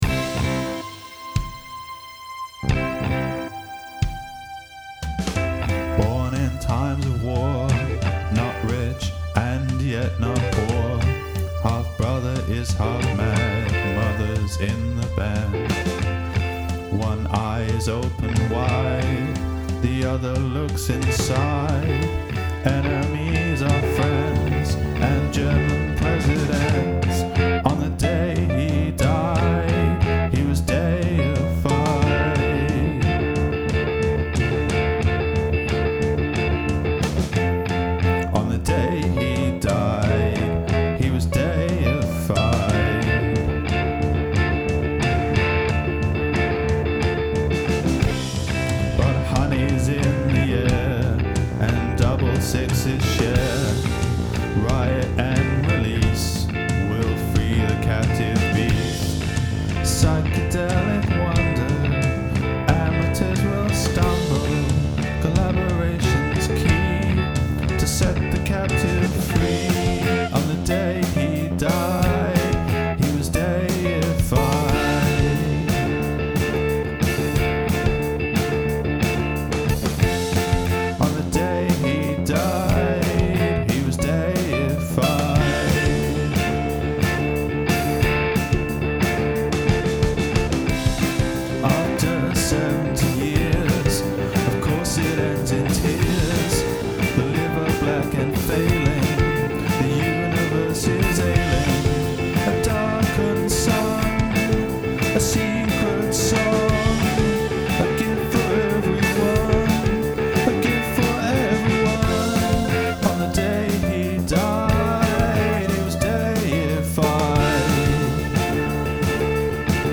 Use a palindromic musical phrase of at least 6 notes total (melody must be a palindrome but rhythm can change)
The guitars have plenty of tone (though they get a bit clippy as as the song goes on), and the drums and bass sound good. Performance-wise there's very much a first take feel about the guitars and vocals.
Those haunting lead guitar melodies in the chorus are great though, particularly the bit on the second repetition of each chorus.